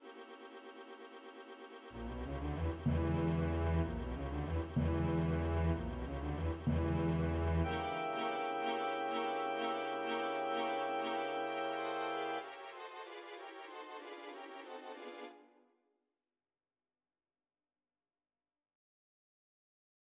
엑토르 베를리오즈의 ''환상 교향곡''의 마지막 악장은 베버의 영향을 반영하여[19] 마녀의 안식일의 섬뜩한 분위기를 불러일으키기 위해 감7화음을 풍부하게 사용한다.